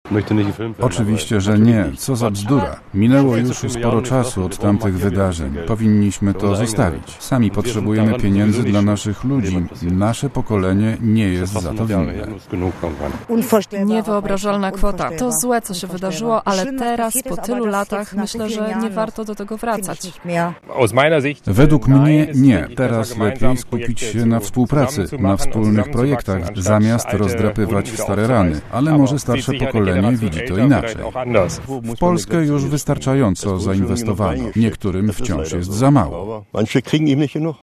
Sona została przeprowadzona przez Radio Szczecin wśród mieszkańców niemieckiego Schwedt, miasteczka oddalonego o 40 km od polskiej granicy.